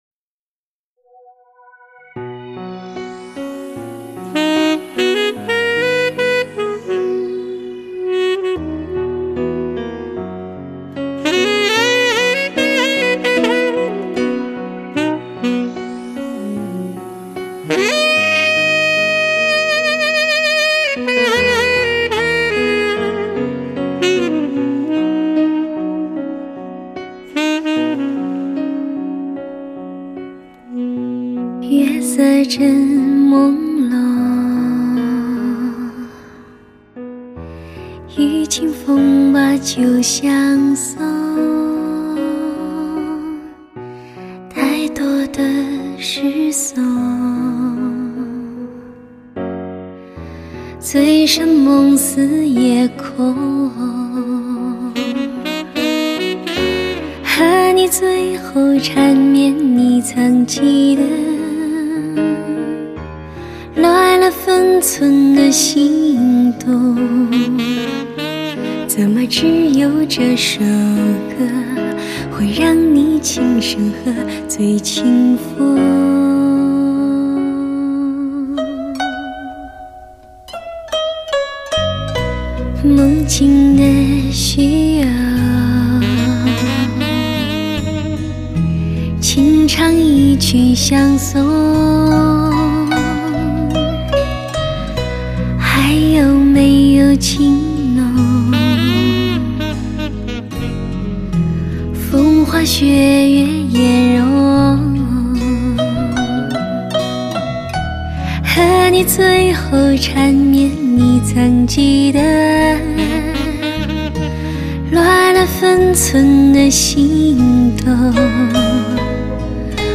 唱片类型：华语流行
一把有典雅味道，清纯美感的歌喉，不经意流露出东方女性的妩媚，性感和生命活力。
真声”、“戏曲唱腔”，多了点忧伤的唱法堪称发烧音乐女王。